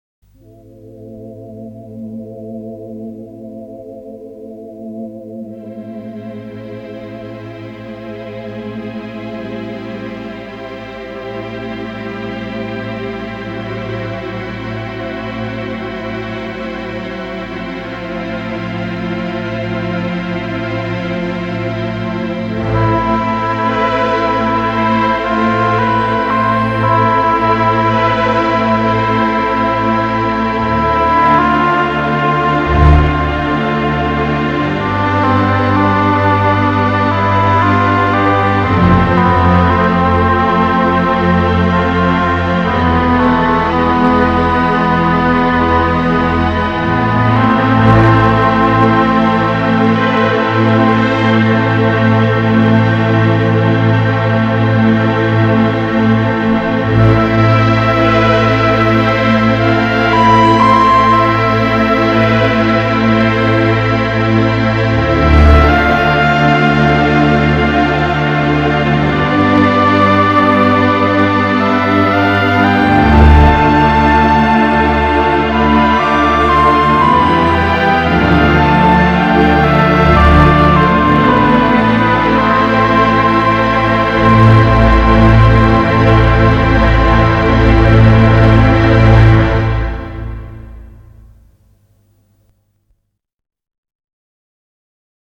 장르: Electronic, Jazz, Pop
스타일: Modern Classical, Easy Listening